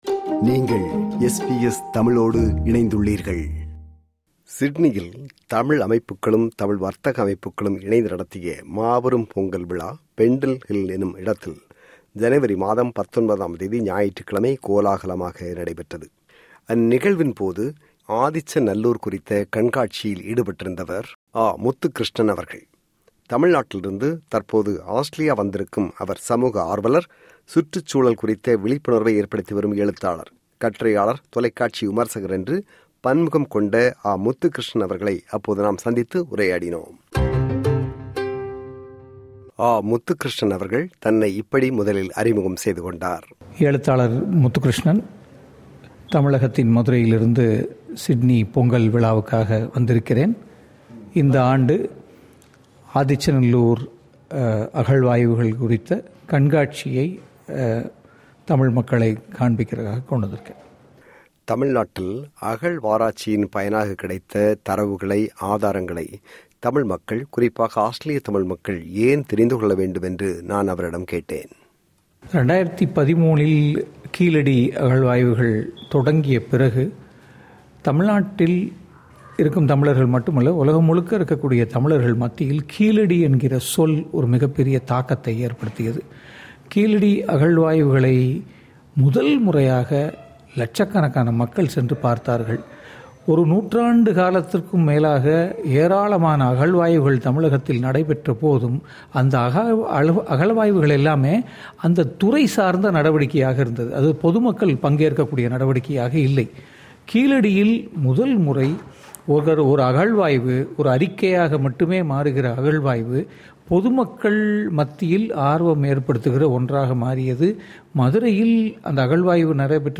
சந்தித்து உரையாடுகிறார்